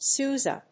/ˈsuzʌ(米国英語), ˈsu:zʌ(英国英語)/